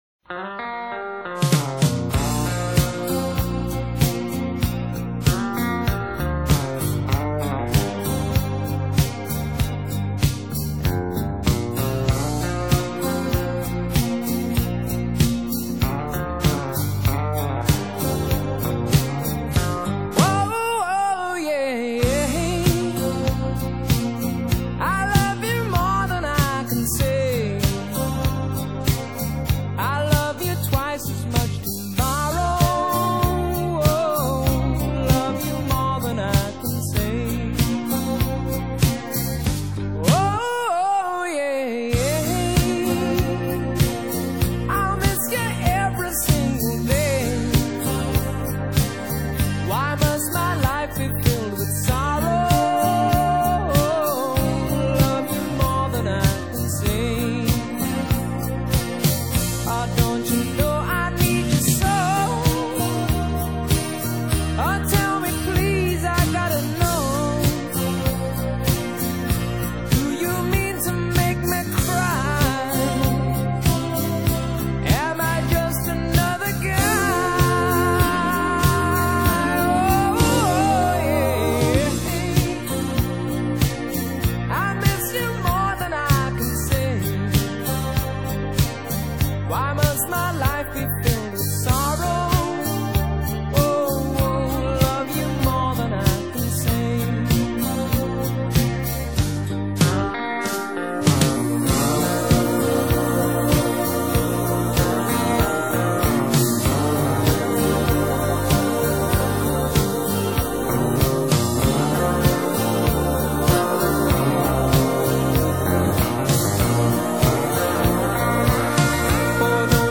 Pop | MP3 CBR 320 Kbps | 180 MB | Cover |